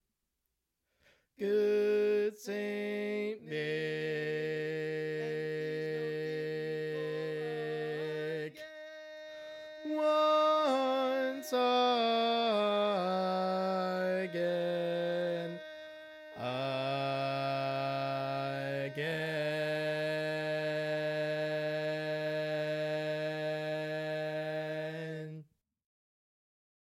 Key written in: E♭ Major
Type: Barbershop